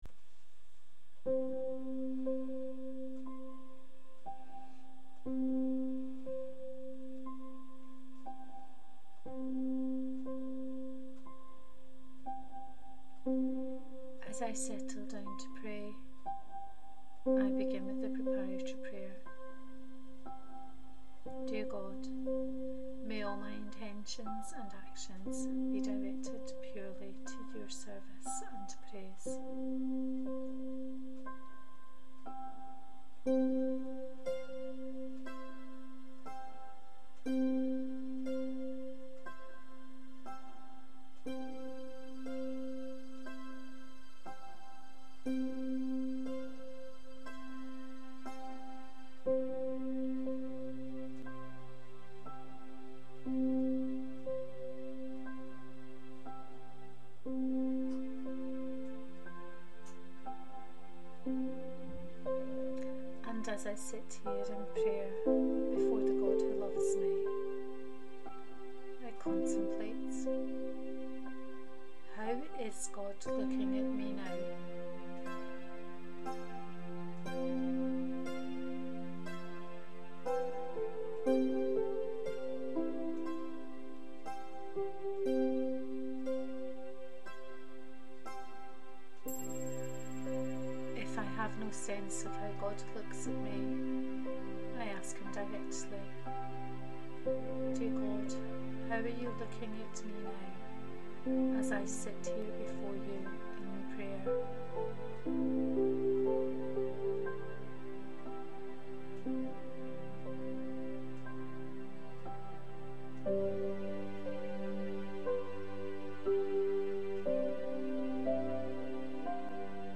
Praying with the icon Christ of Maryknoll. Guided prayer.